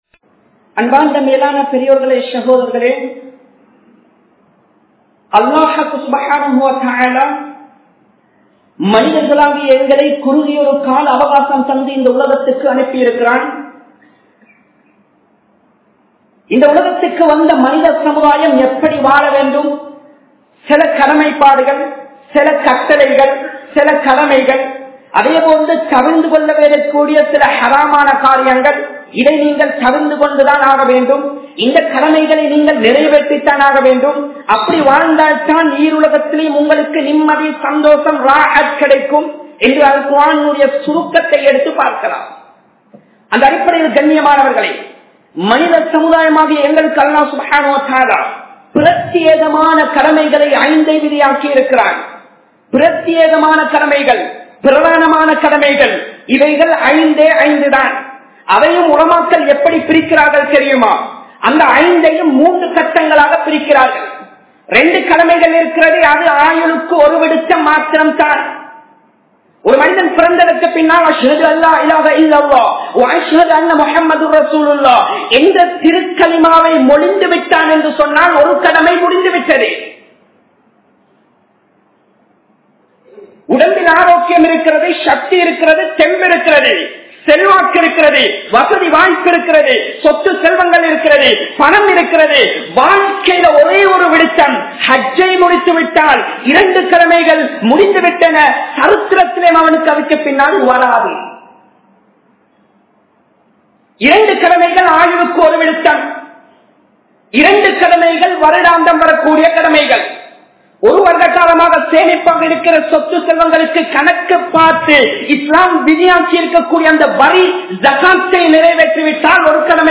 Thariththiriyam Pidiththa Manitharhal Yaar? (தரித்திரியம் பிடித்த மனிதர்கள் யார்?) | Audio Bayans | All Ceylon Muslim Youth Community | Addalaichenai
Colombo 11, Samman Kottu Jumua Masjith (Red Masjith)